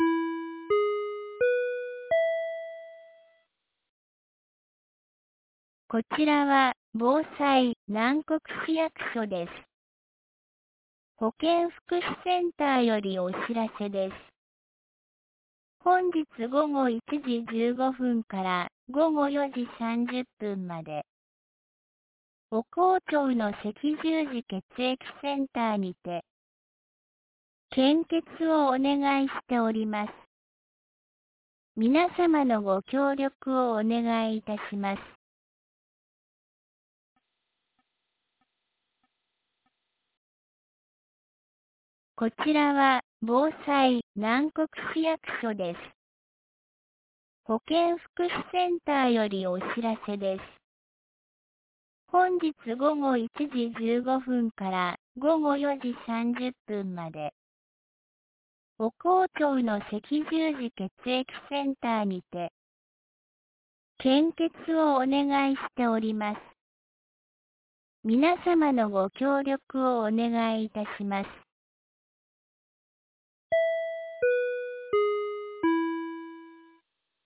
2025年11月10日 10時01分に、南国市より放送がありました。
放送音声